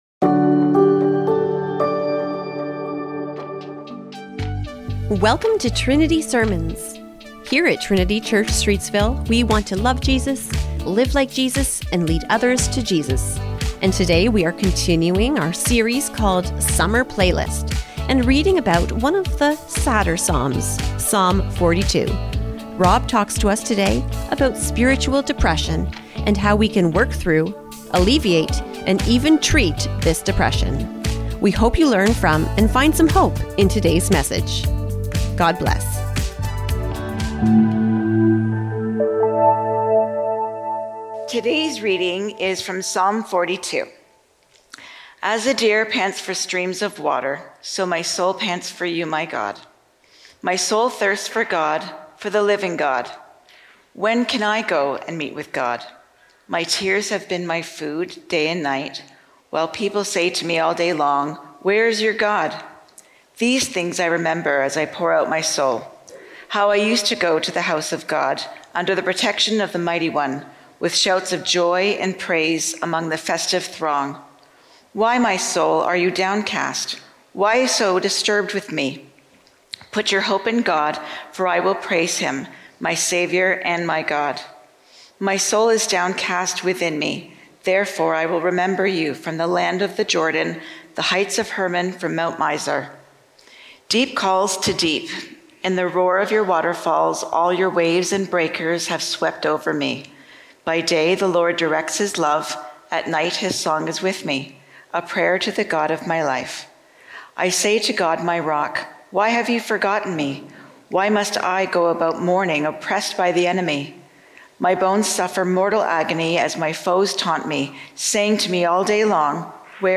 Trinity Streetsville - Summertime Sadness | Summer Playlist | Psalms | Trinity Sermons